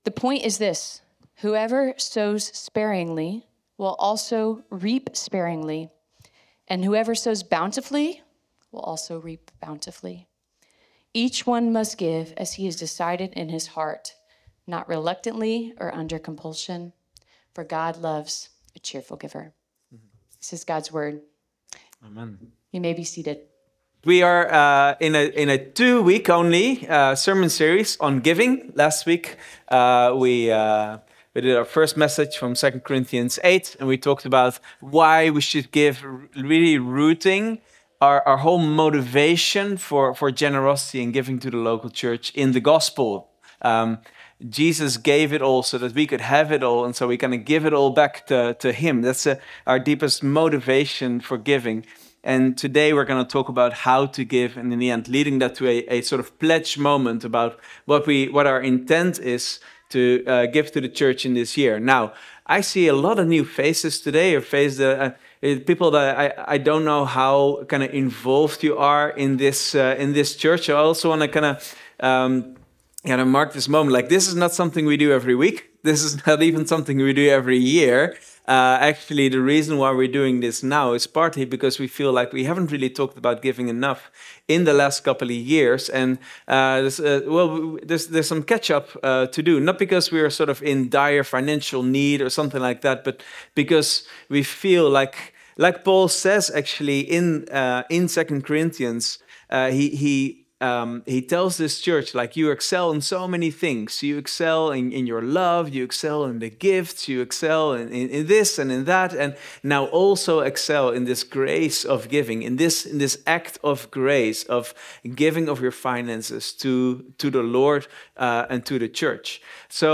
Vineyard Groningen Sermons